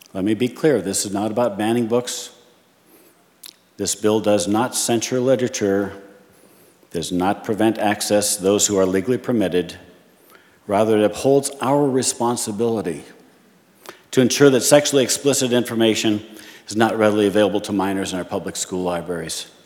Jamestown Republican Representative Bernie Satrom spoke in favor of the measure.